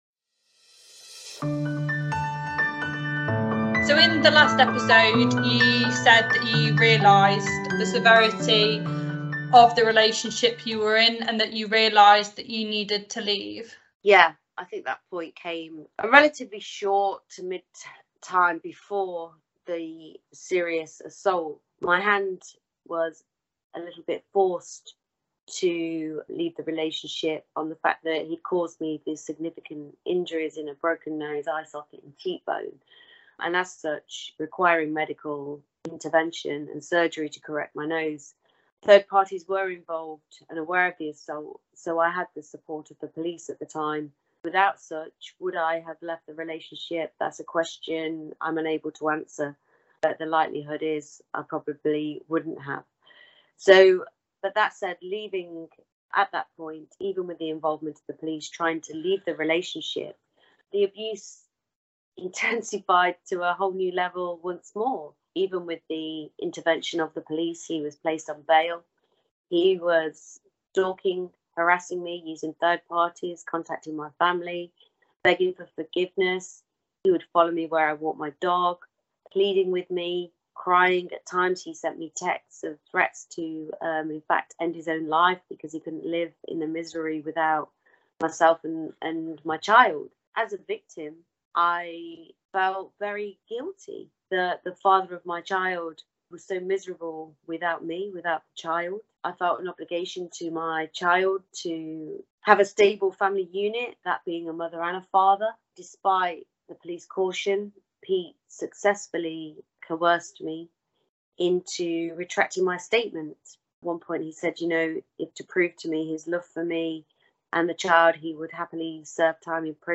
Interview with a victim episode 4